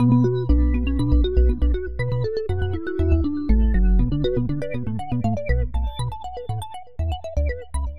Moonbeam Tonnage 音乐盒层
Tag: 120 bpm Fusion Loops Piano Loops 1.35 MB wav Key : Unknown